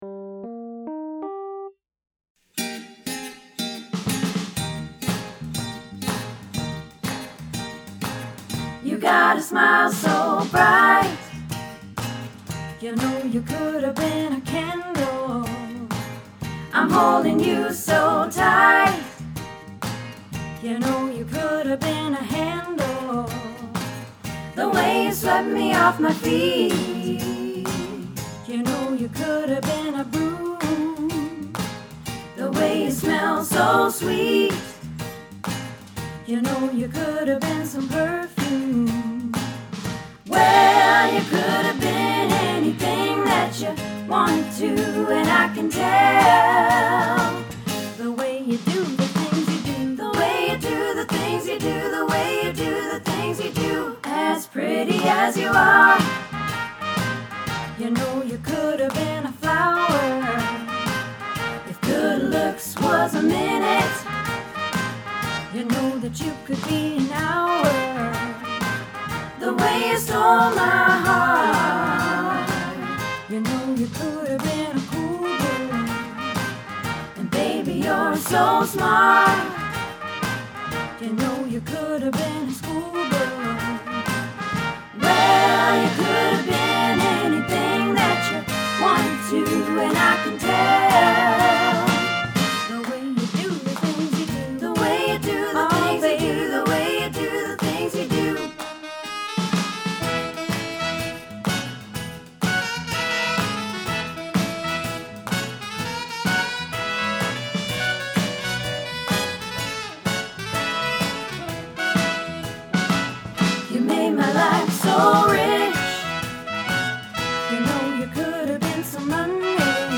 The Way You Do the Things You Do - Practice